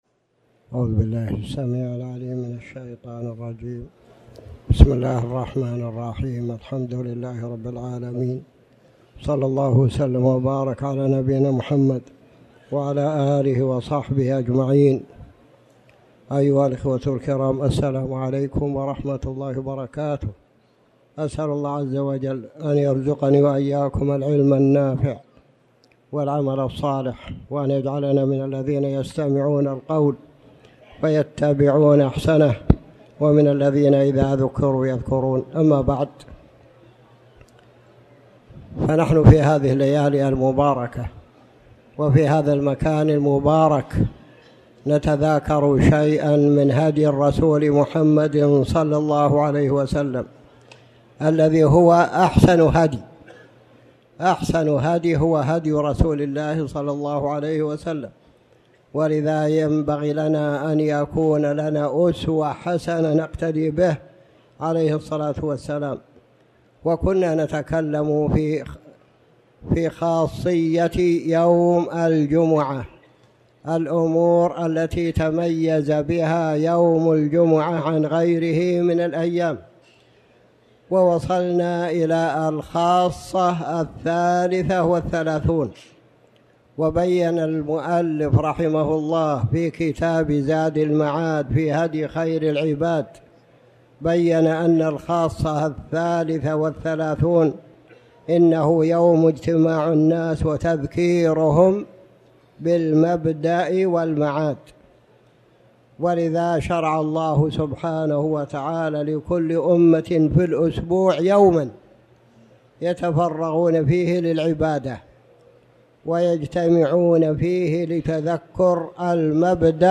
تاريخ النشر ٢٤ ذو الحجة ١٤٣٩ هـ المكان: المسجد الحرام الشيخ